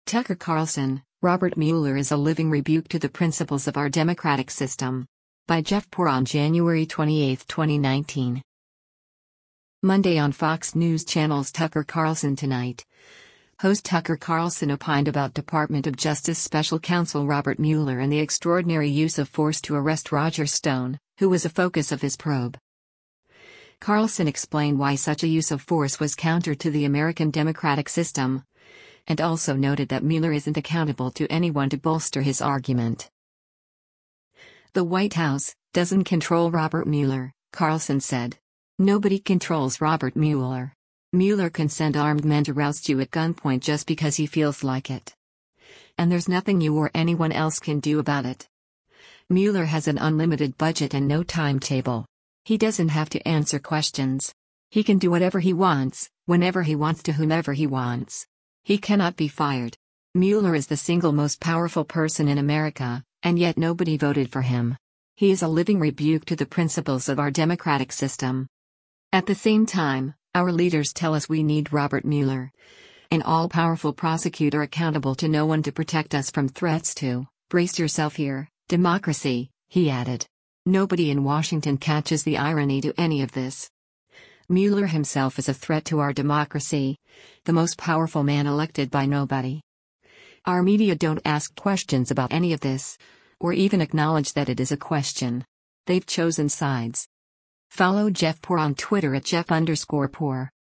Monday on Fox News Channel’s “Tucker Carlson Tonight,” host Tucker Carlson opined about Department of Justice special counsel Robert Mueller and the extraordinary use of force to arrest Roger Stone, who was a focus of his probe.